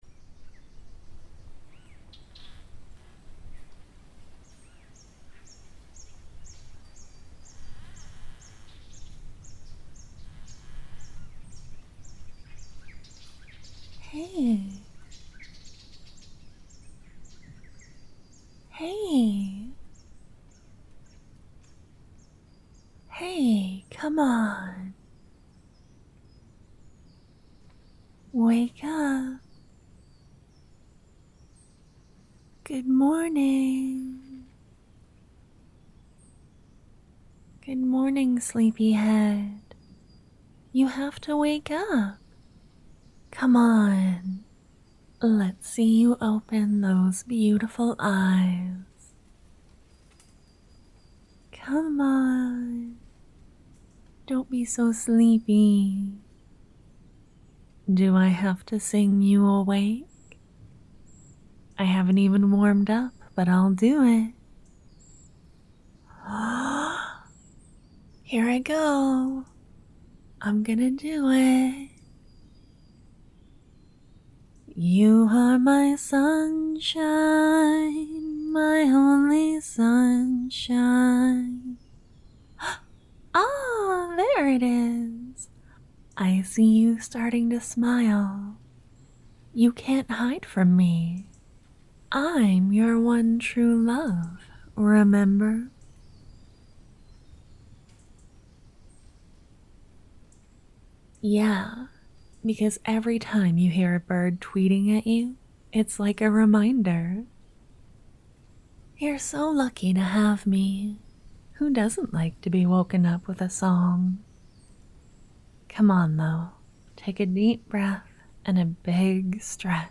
You wake the sound of birds and her beside you, trying to coax you awake~!I hope you enjoy~!